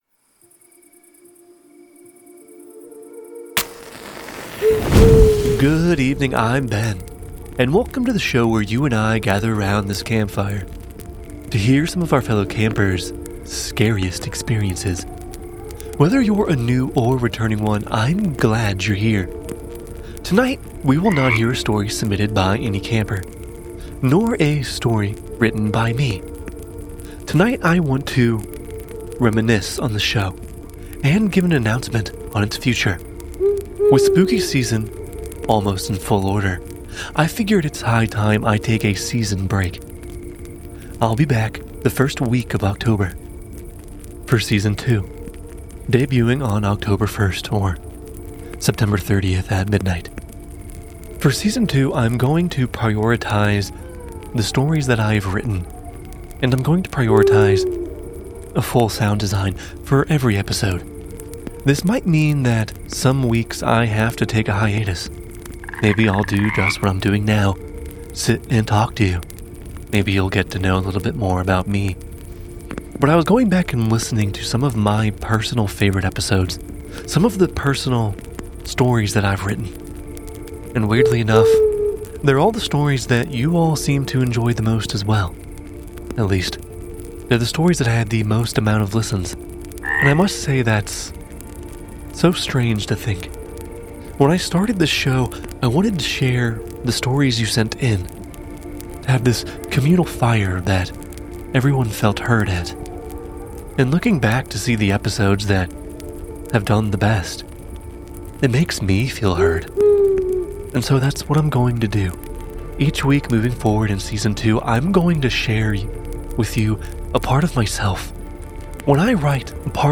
Narrated by: